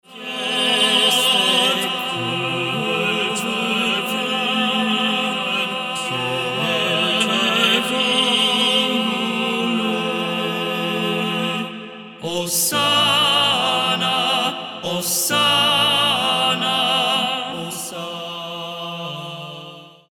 four male voices
Byzantine Orthodox Songs